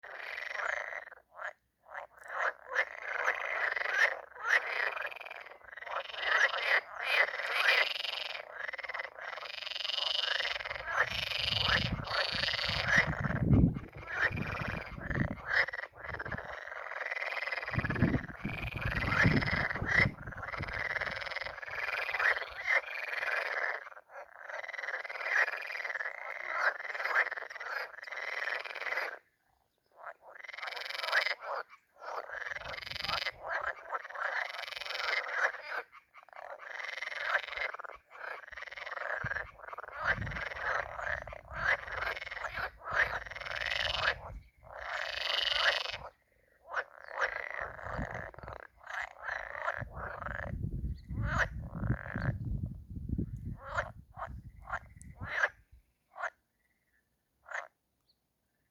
Common tree frog, Hyla arborea
StatusSinging male in breeding season